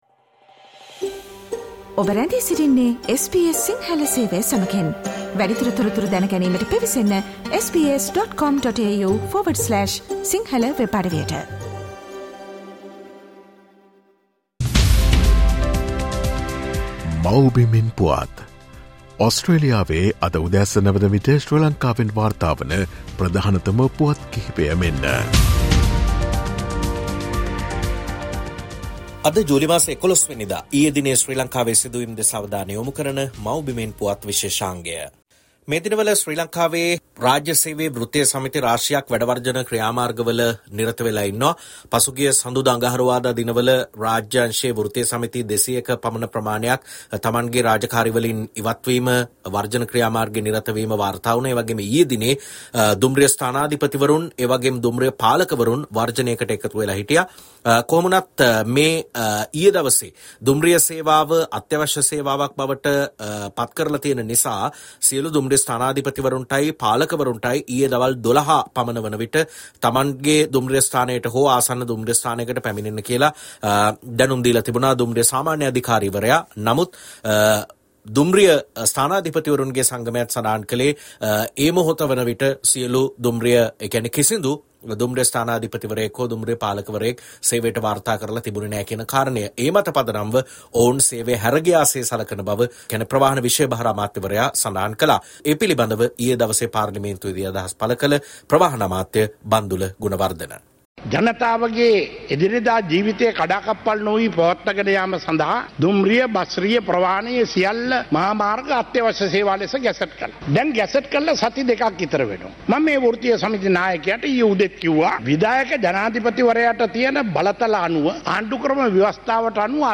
The latest news reported from Sri Lanka from "Homeland News” feature